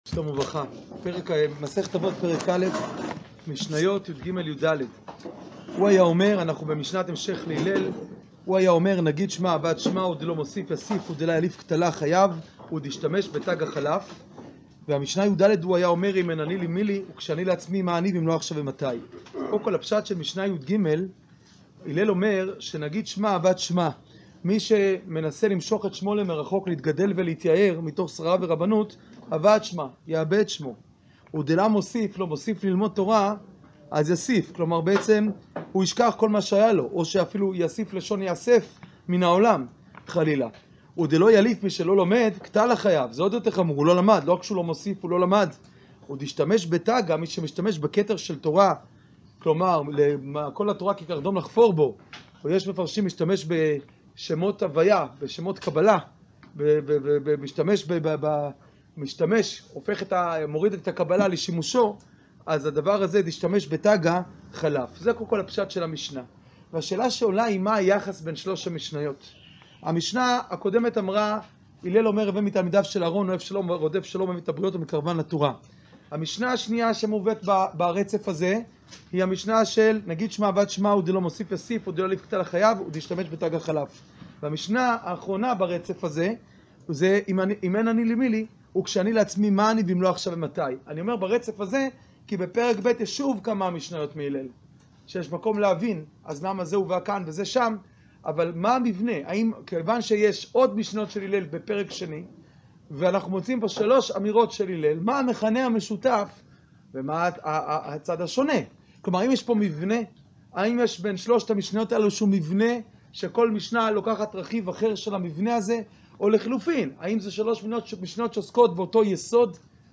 שיעור פרק א משנה יג'